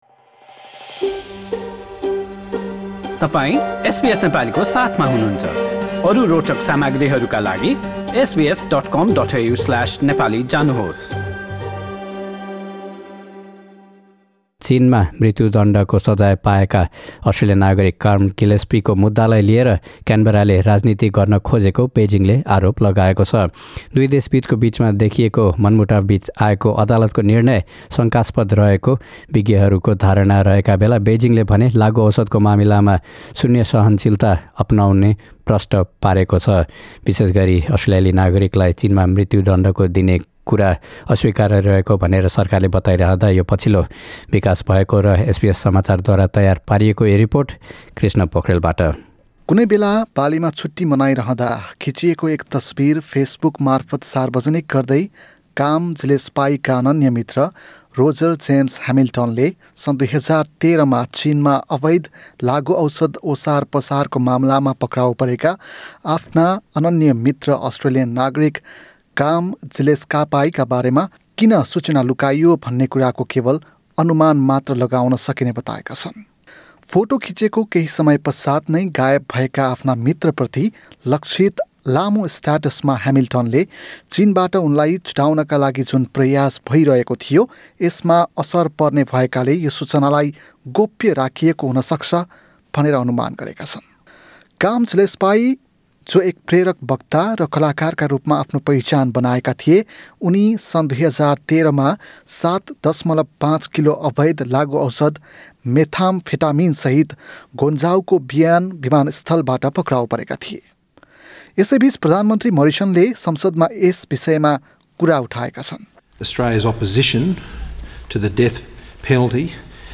एसबीएस समाचारद्वारा तयार पारिएको यो रिपोर्ट